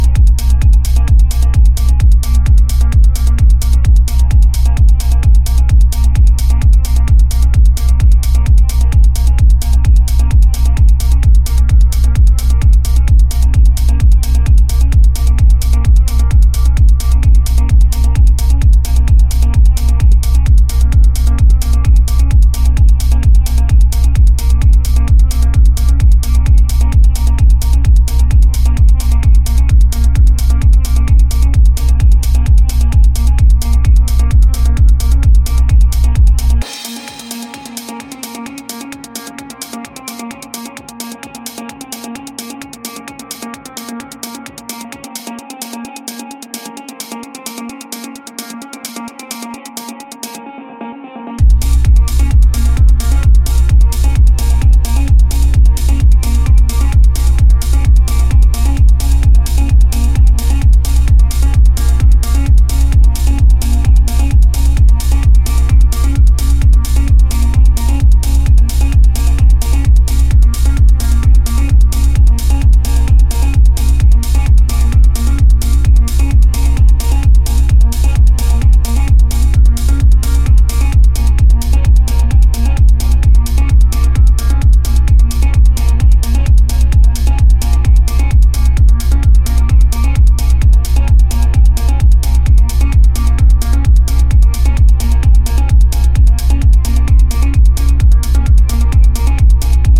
studio album
electronic music